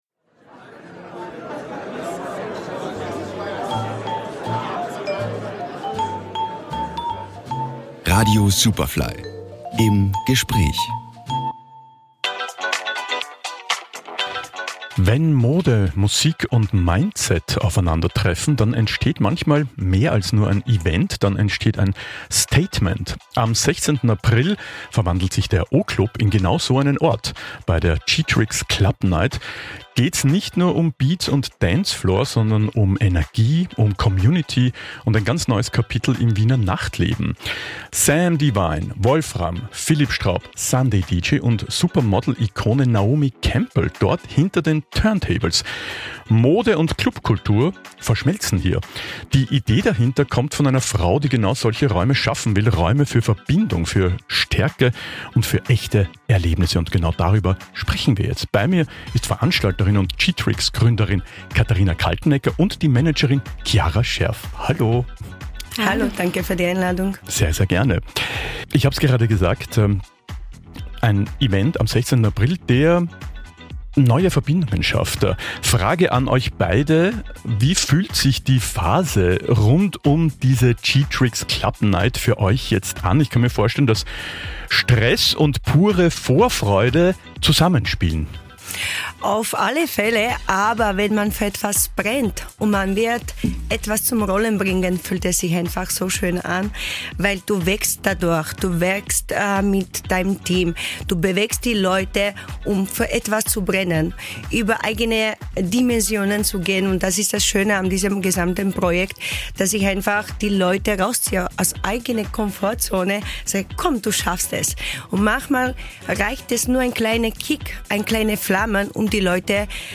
Superfly Featured | Im Gespräch